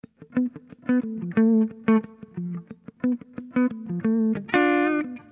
Loops guitares rythmique- 100bpm 3
Guitare rythmique 57